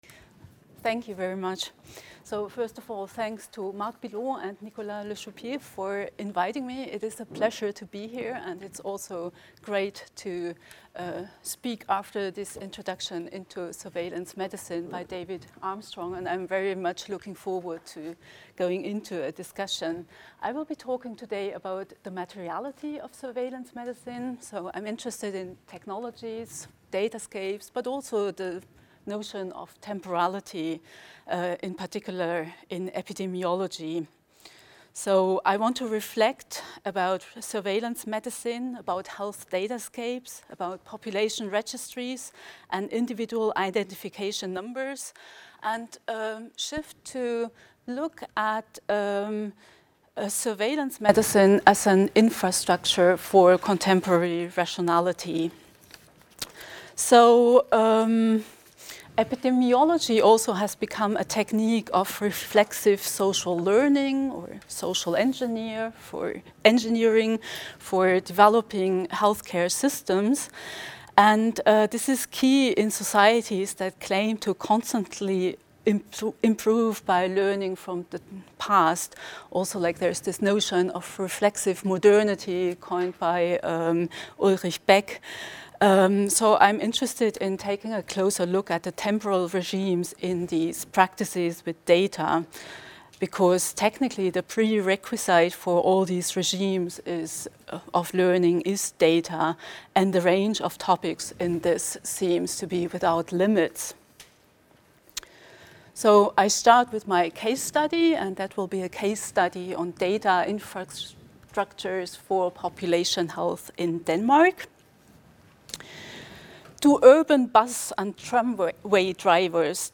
Intervention au séminaire formes de surveillance en médecine et santé publique.